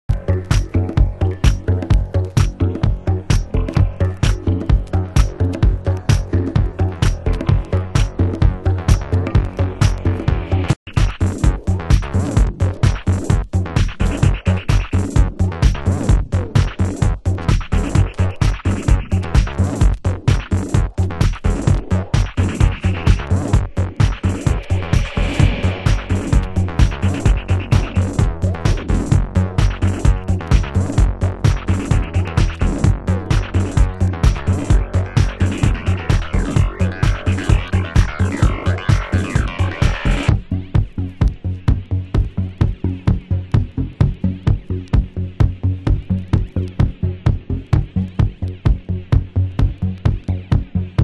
★DISOCDUB NUHOUSE